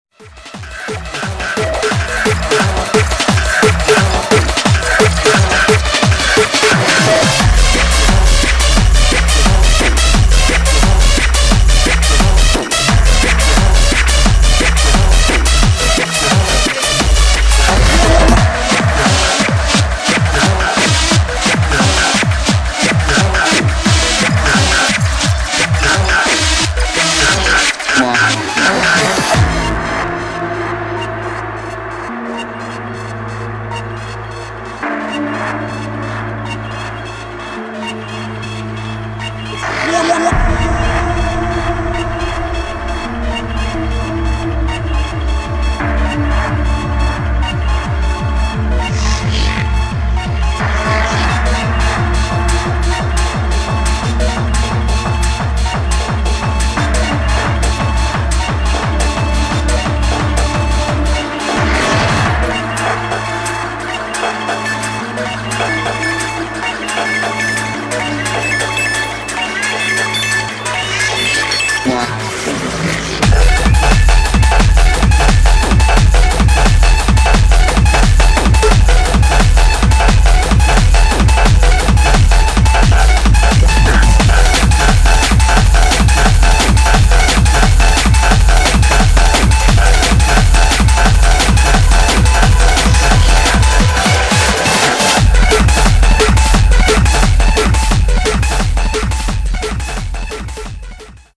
[ DRUM'N'BASS / HARDCORE / BREAKCORE ]